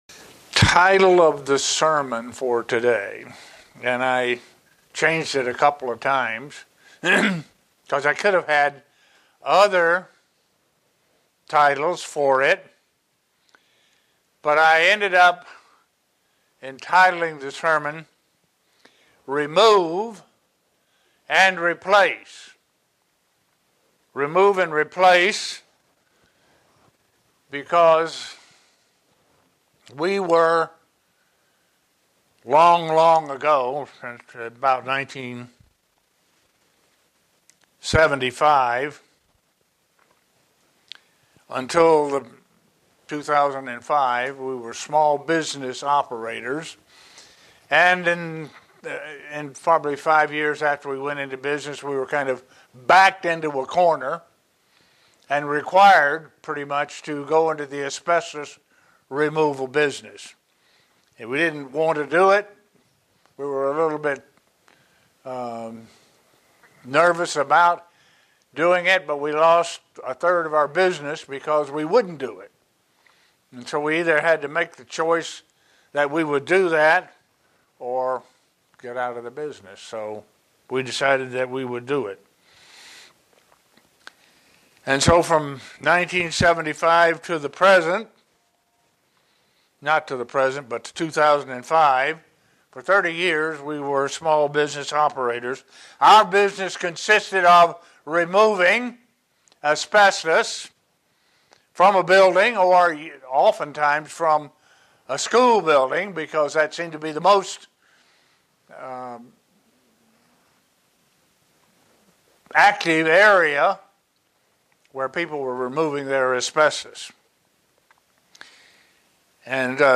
Sermons
Given in Buffalo, NY Elmira, NY